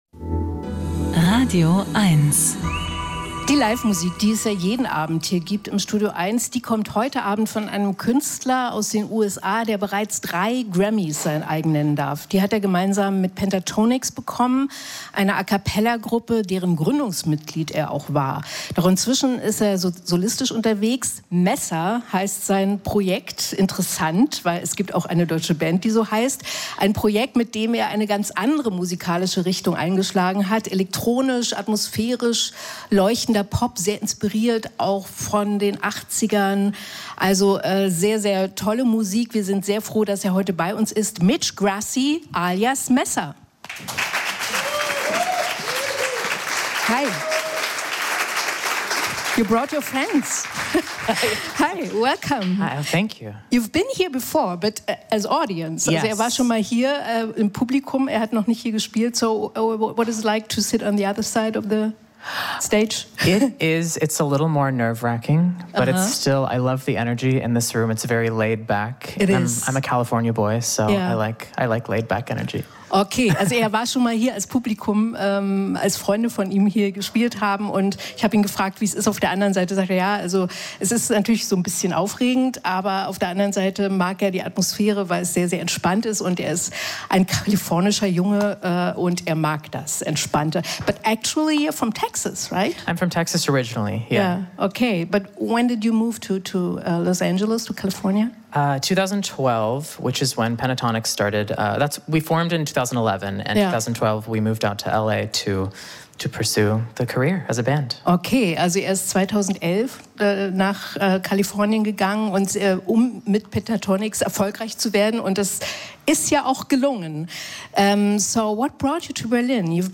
Jeden Sonntag von 14.00 bis 16.00 Uhr lädt Bettina Rust einen prominenten Gast in die Hörbar Rust ein. Dieser stellt sich in der Sendung vor und die Musik, die in seinem Leben von Bedeutung war und erzählt dazu die passenden Geschichten zum Soundtrack seines Lebens - alte Mixtapes werden rausgekramt - die erste Platte - intime Töne oder peinliche Songs mit persönlichen Erinnerungen des jeweiligen Gastes.